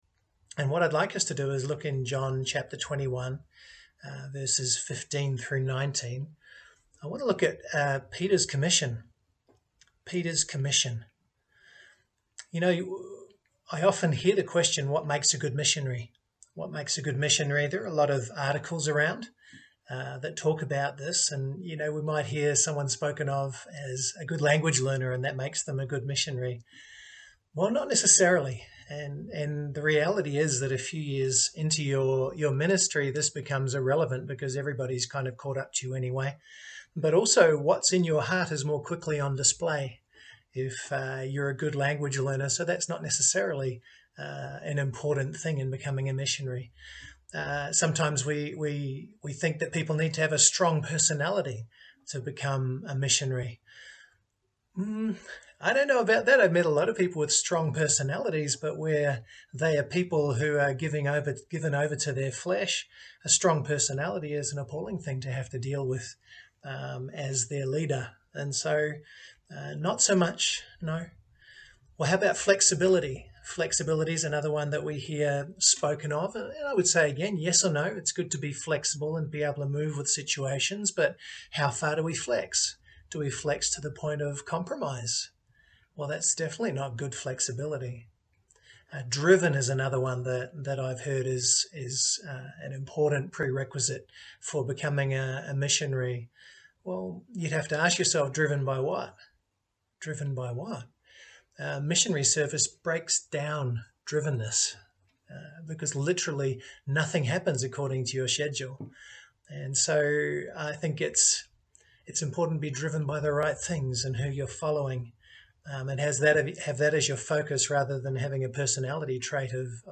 Preacher
Passage: John 21:15-19 Service Type: Sunday Morning